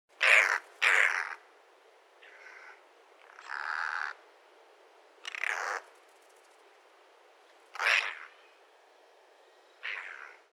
クロアジサシ｜日本の鳥百科｜サントリーの愛鳥活動
「日本の鳥百科」クロアジサシの紹介です（鳴き声あり）。